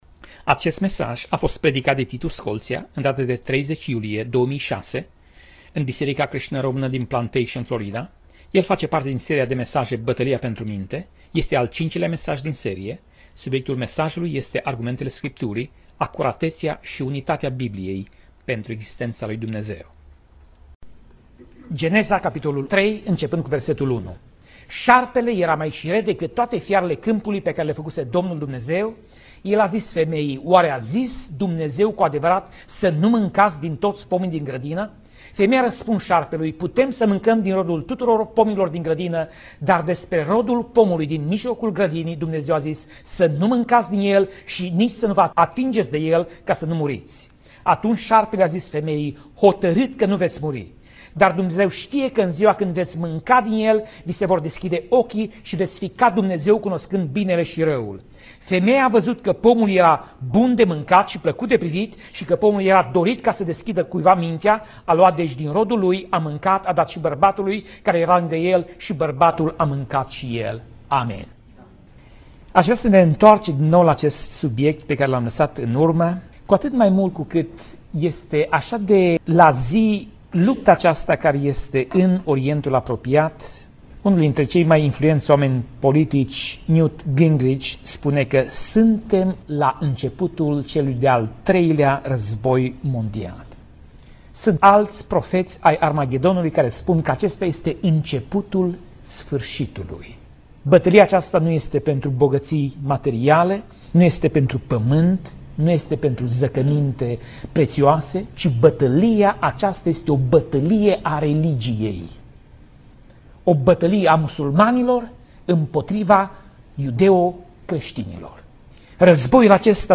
Pasaj Biblie: Geneza 3:1 - Geneza 3:6 Tip Mesaj: Predica